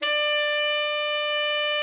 TENOR 31.wav